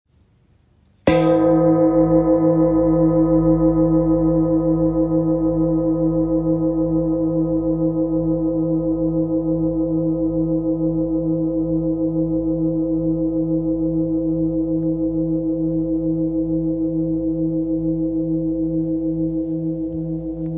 Large Grounding Bowl – 49cm
The rich, resonant tones of the Large Grounding Bowl create a deep and immersive soundscape that soothes the mind, harmonizes the chakras, and releases built-up tension.
Its size and deep tones make it a standout piece in any sound healing practice or sacred space.